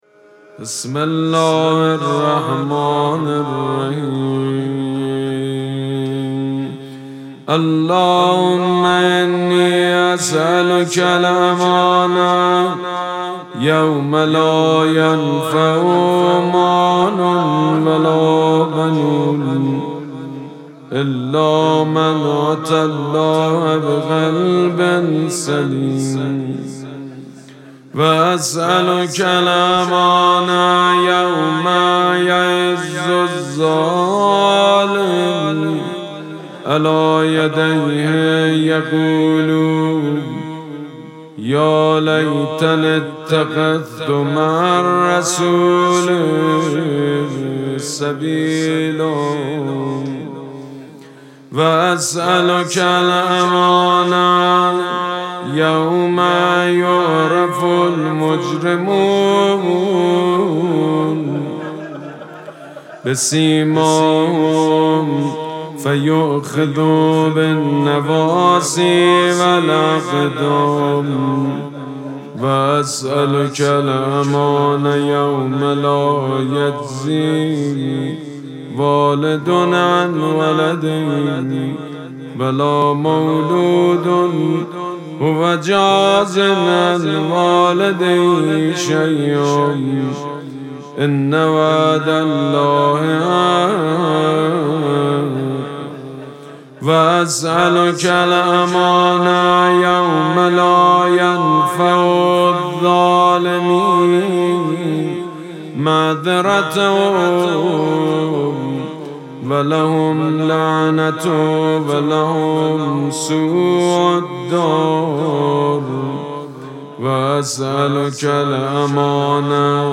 مراسم مناجات شب اول ماه مبارک رمضان شنبه ۱۱ اسفند ماه ۱۴۰۳ | ۳۰ شعبان ۱۴۴۶ حسینیه ریحانه الحسین سلام الله علیها
سبک اثــر مناجات مداح حاج سید مجید بنی فاطمه